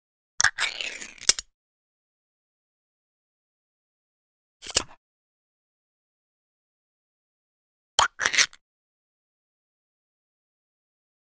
household
Metal Small Tin Fruit Cup Peel Off Lid